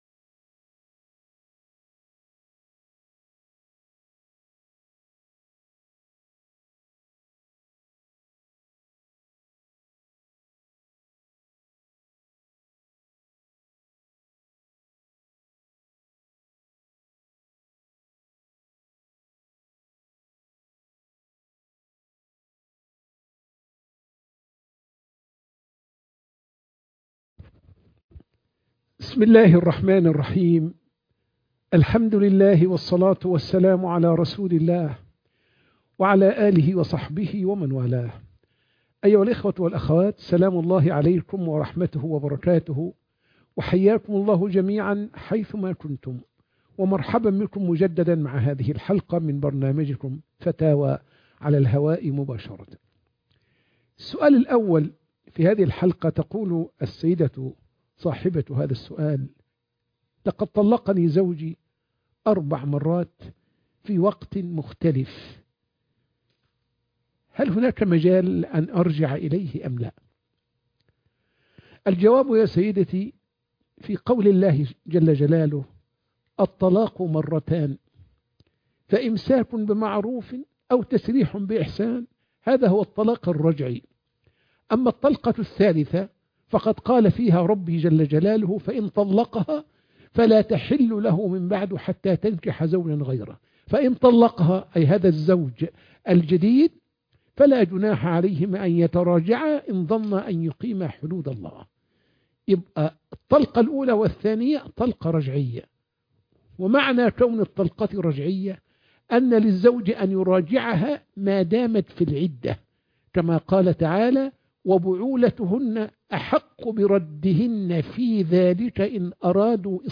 فتاوى على الهواء مباشرة (14)